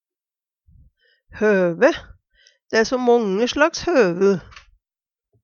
høve - Numedalsmål (en-US)